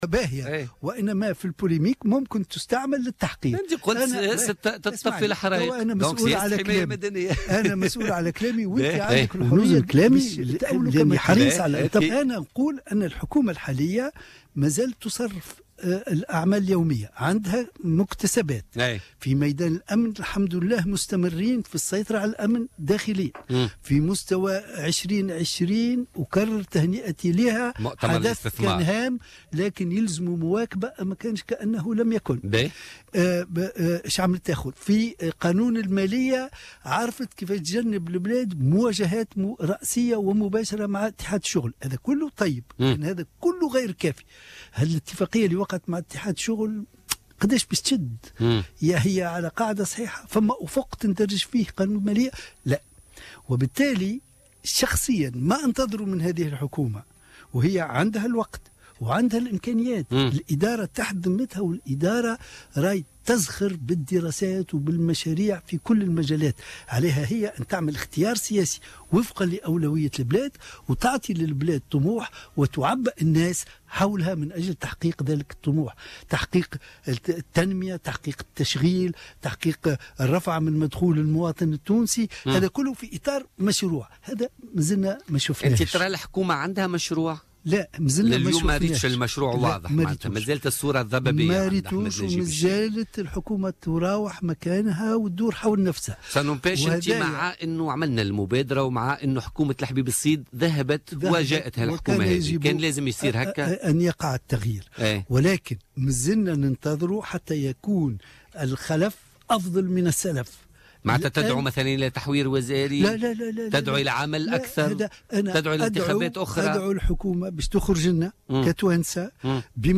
وقال ضيف "بوليتيكا" في "الجوهرة أف أم"، إن الظرف الحالي يقتضي احداث ائتلاف وتكتل وتجمع سياسي ديمقراطي اجتماعي، منتقدا المشهد السياسي التونسي القائم على الثقافة القبلية وليس الحزبية، بحسب تعبيره.